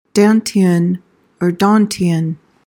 PRONUNCIATION:
(DAN-tee-uhn, dan-TEE-uhn)